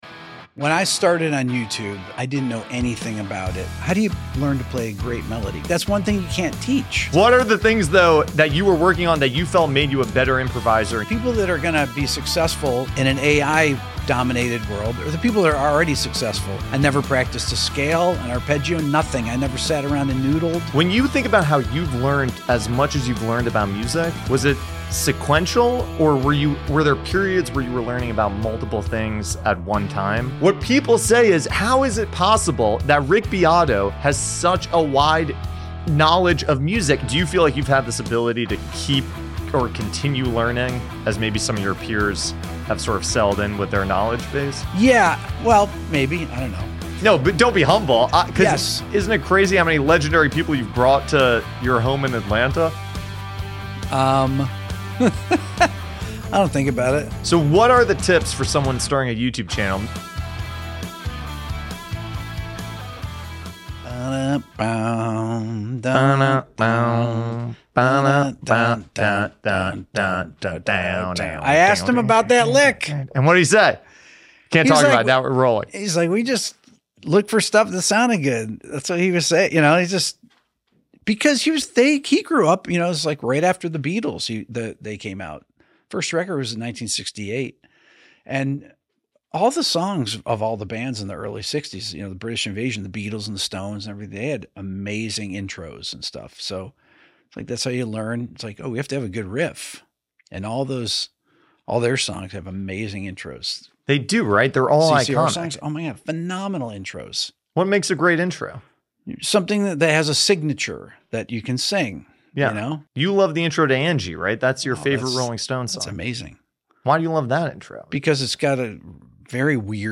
I interview the YouTube master himself Hosted by Simplecast, an AdsWizz company.